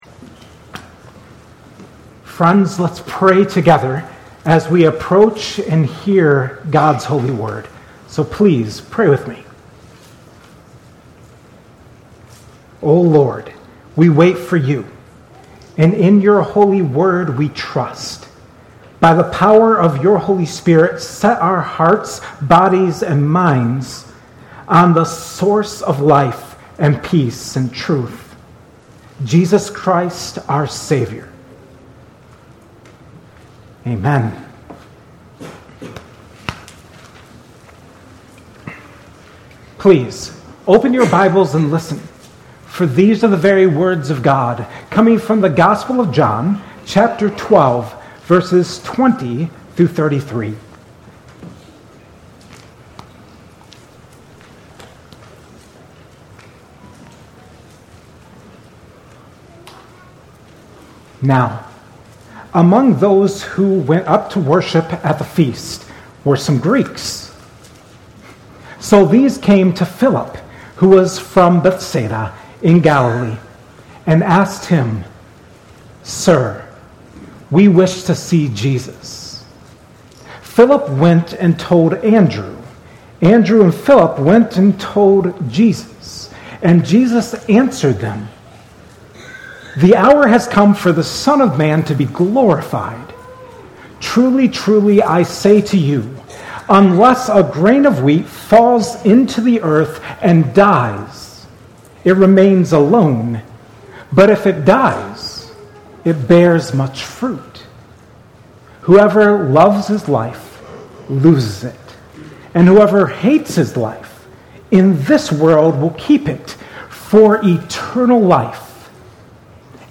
2026 at Cornerstone Church in Pella.